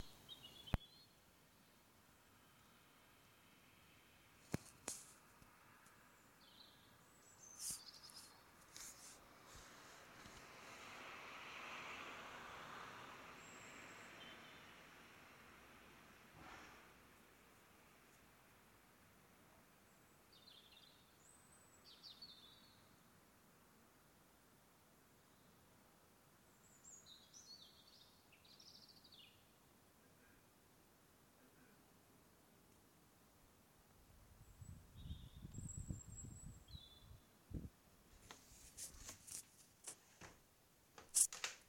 is it normal for birds to be singing in my back garden this late? what are they?